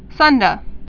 (sŭndə, sndə)